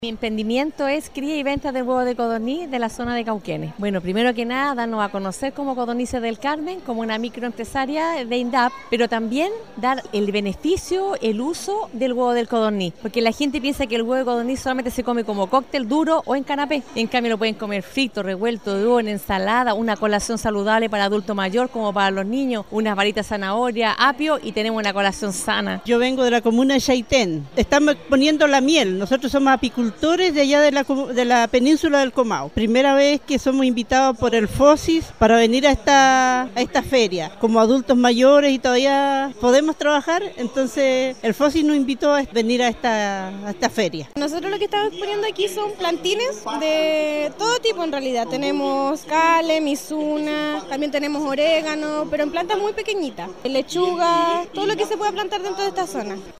Expositores que participaron de la ExpomundoRural 2019 en Frutillar, destacaron la actividad como positiva para mostrar sus productos y hacer sociatividad con los demás pequeños agricultores de otros puntos del país.